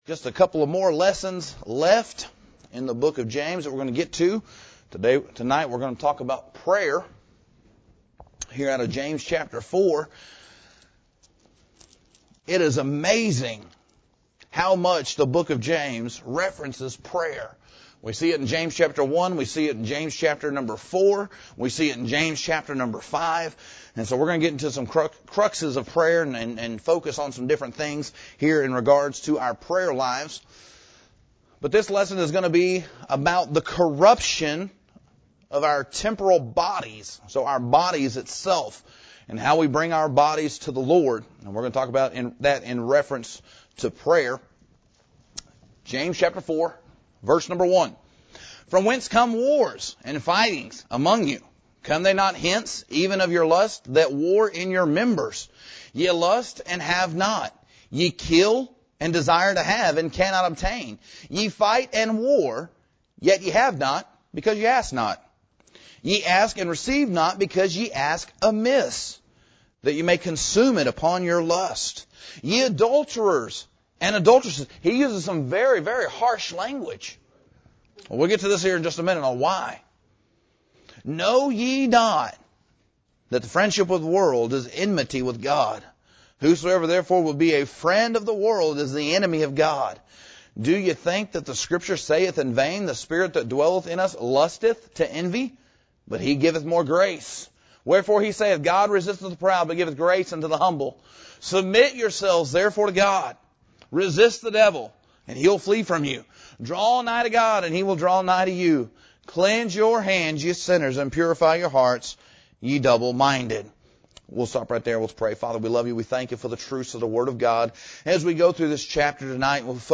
Midweek Service Expository